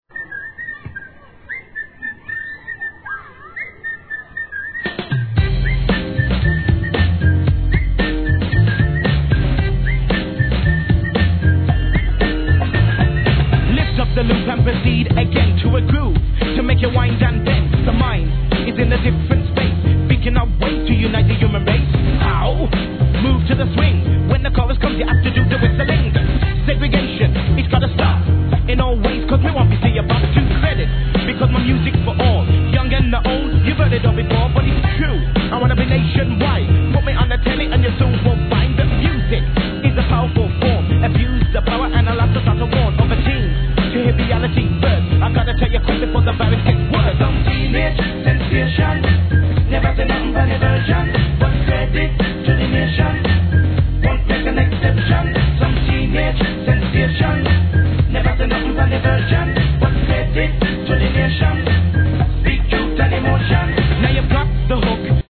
HIP HOP/R&B
UK 要クリックHIP HOPバンド